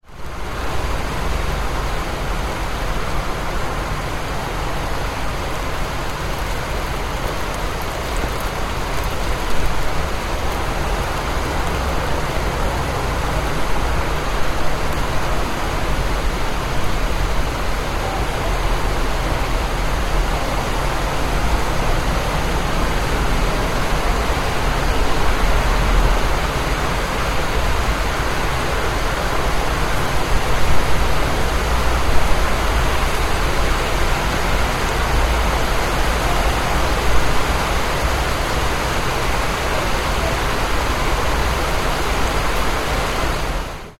Heavy Rain And Cars On A Wet Road Sound Effect
Description: Heavy rain and cars on a wet road sound effect. Car and tire noise on a wet road or asphalt with background traffic roar. White noise of rain and cars, city ambience in stormy weather. Street sounds.
Heavy-rain-and-cars-on-a-wet-road-sound-effect.mp3